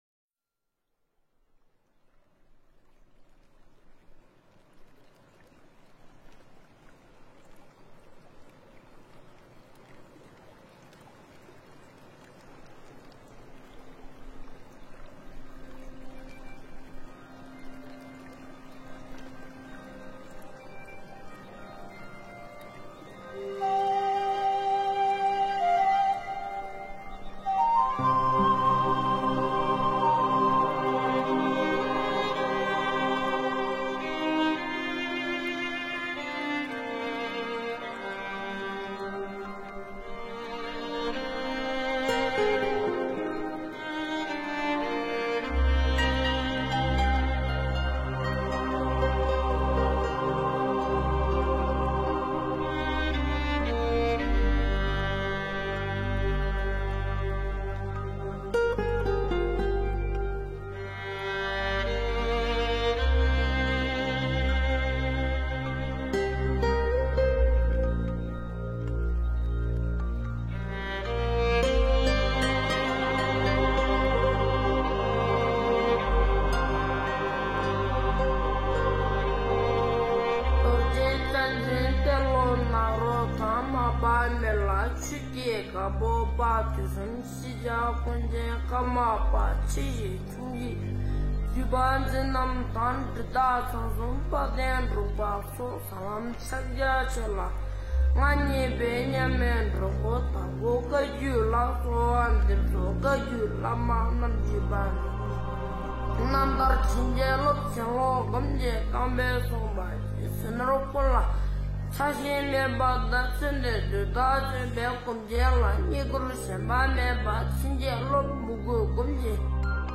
大手印传承多桀羌 诵经 大手印传承多桀羌--大宝法王 点我： 标签: 佛音 诵经 佛教音乐 返回列表 上一篇： 爱的智慧 下一篇： 玛哈卡那护法图像 相关文章 即心念佛--佛音 即心念佛--佛音...